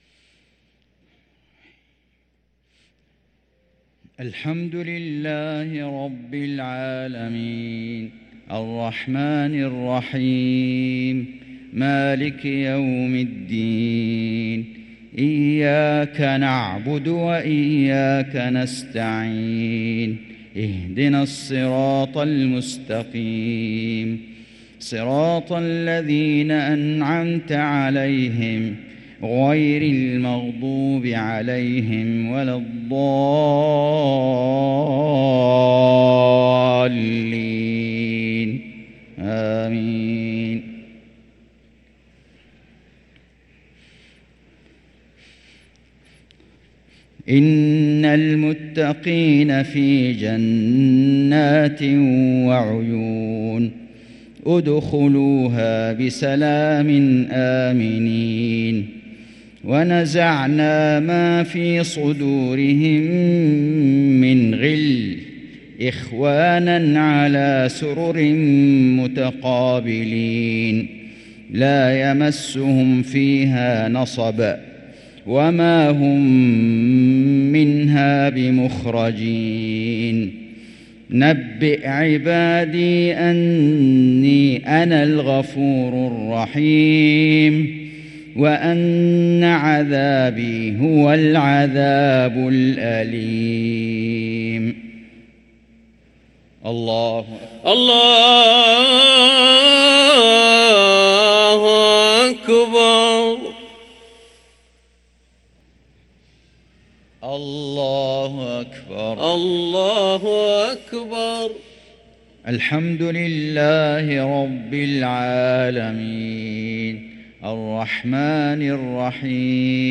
صلاة العشاء للقارئ فيصل غزاوي 1 شوال 1444 هـ
تِلَاوَات الْحَرَمَيْن .